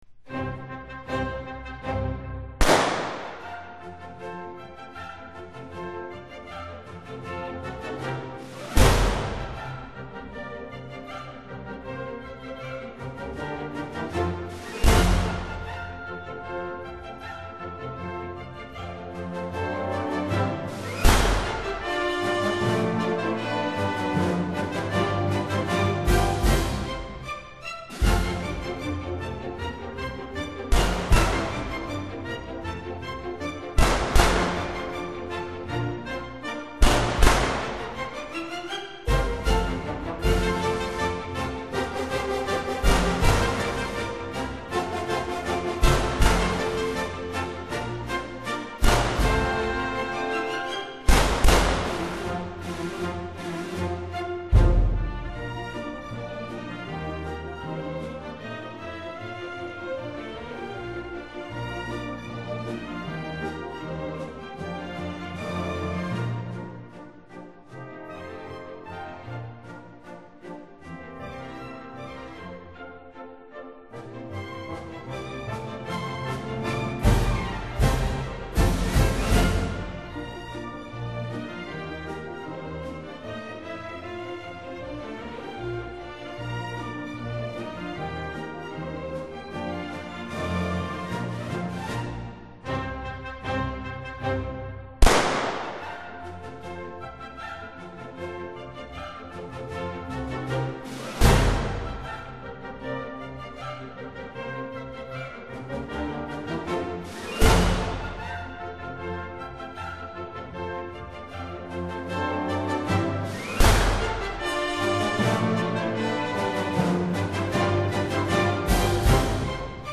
音乐中的圆舞曲，轻快华丽，优美流畅，旋转激扬，欢快的旋律中不时